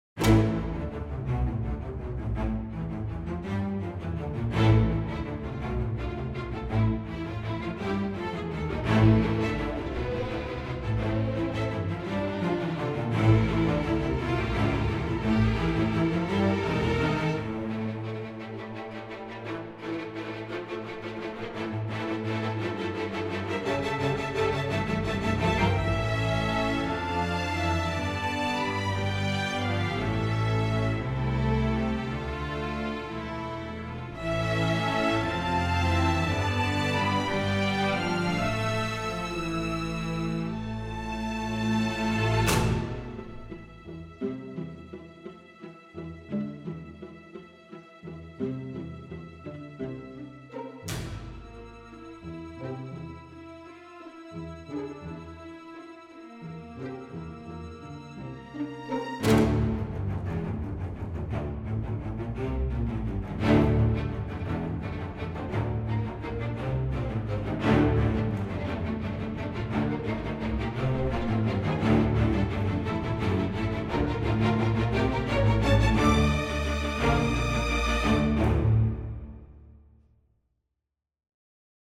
orchestral strings library
The result is a string library that is easy to use, composer-friendly, with unparalleled recording quality.